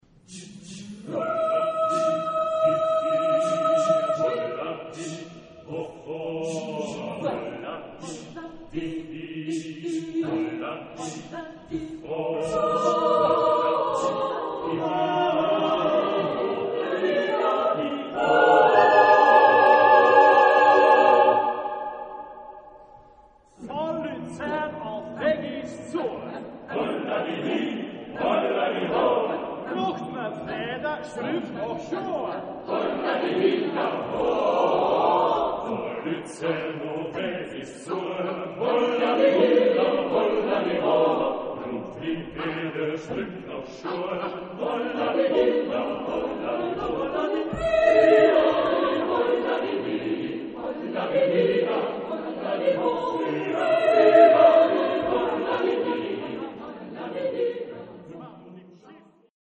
Texte en : Suisse alémanique ; onomatopées
Genre-Style-Forme : Fantaisie ; Folklore ; Profane
Caractère de la pièce : grotesque ; humoristique
Type de choeur : SSAATTBB  (4 voix mixtes )
Solistes : Alto (1) (ad lib)  (1 soliste(s))
Instruments : Cor des alpes ; Clarine (2)
Tonalité : mi bémol majeur